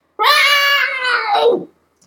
cat.ogg